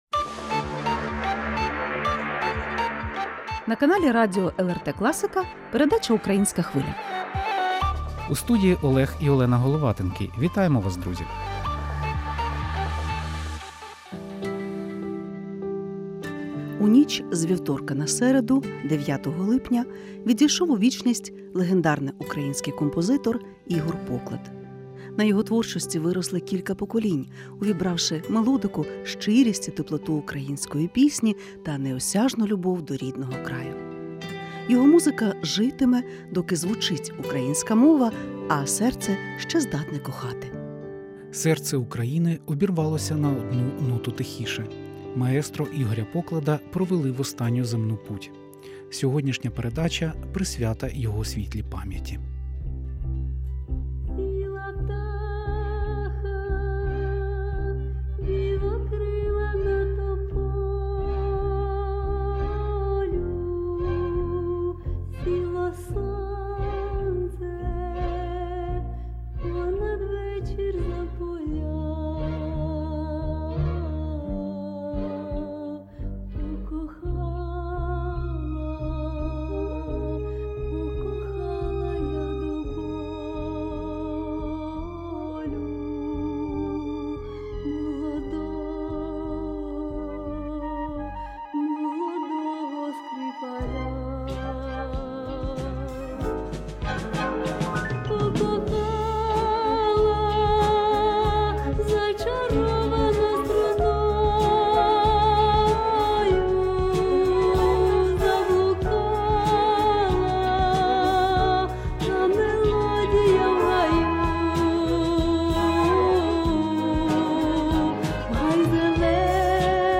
Також прозвучать спогади рідних і близьких маестро, пісні його авторства, що стали шедеврами української естради.